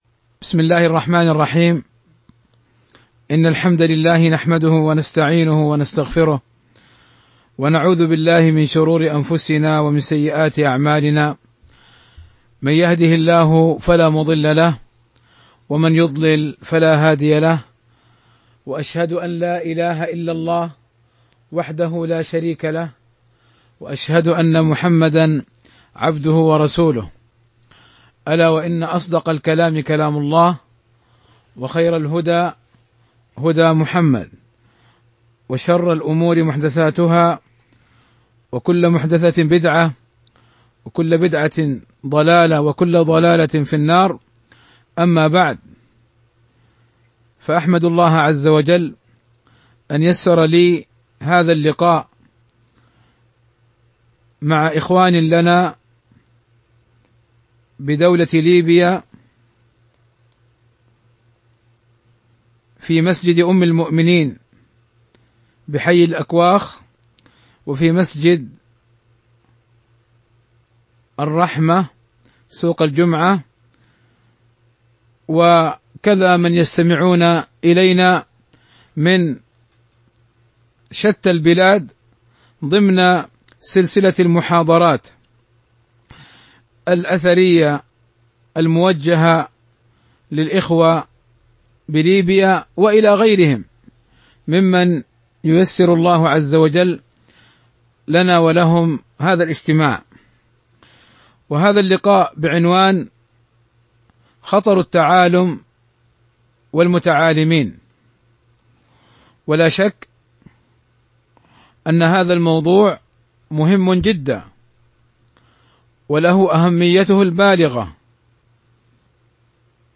خطر التعالم والمتعالمين محاضرة لفضيلة الشيخ